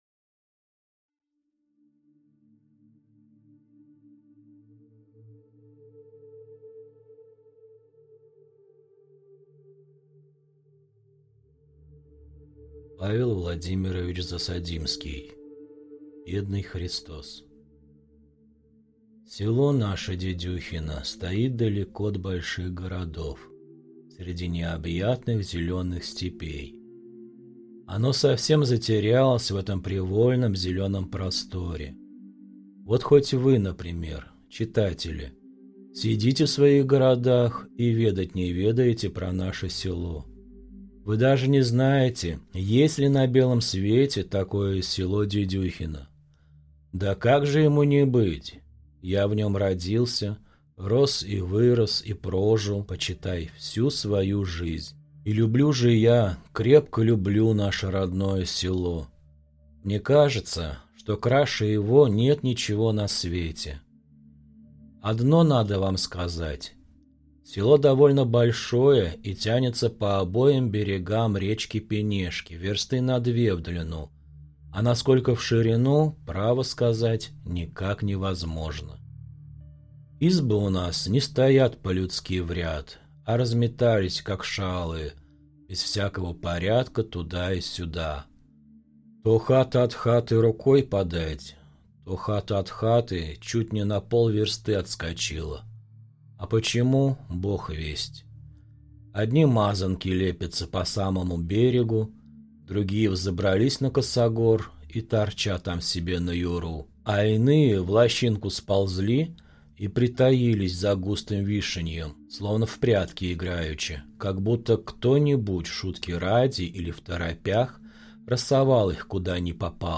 Аудиокнига Бедный Христос | Библиотека аудиокниг